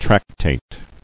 [trac·tate]